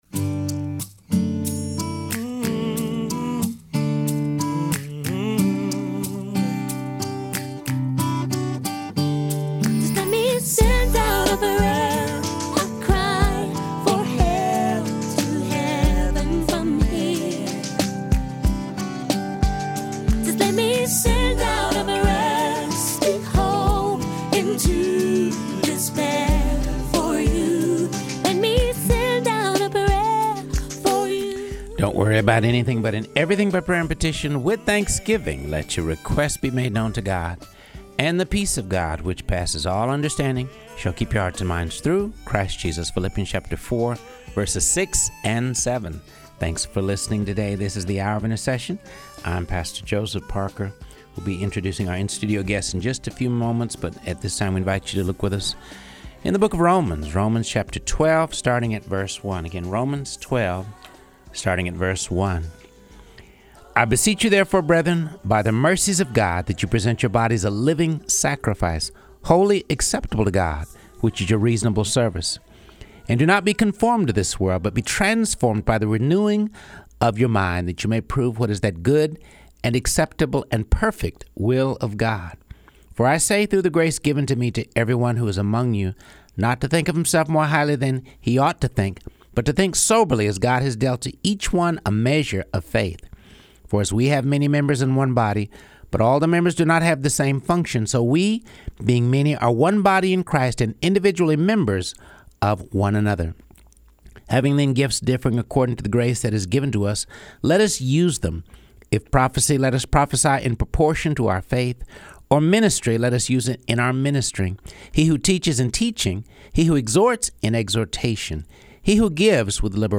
In Studio Guests